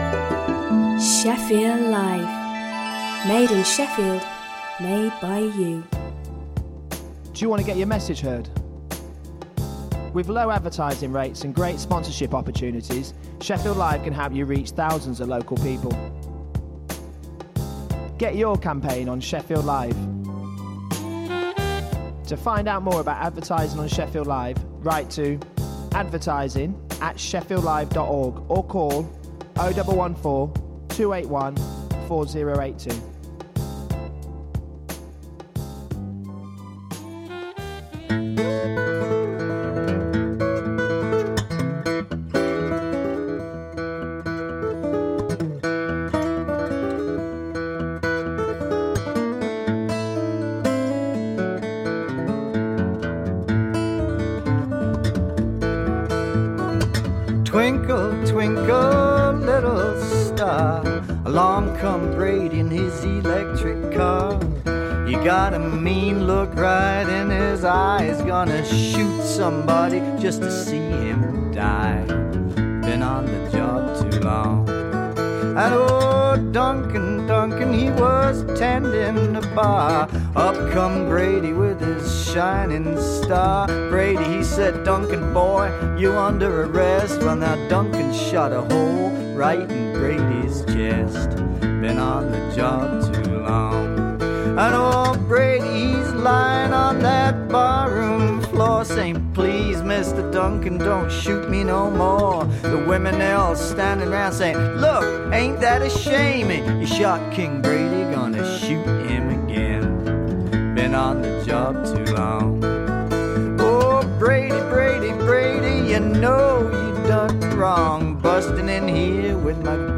Adal Voice of Eritreans is a weekly radio magazine programme for the global Eritrean community.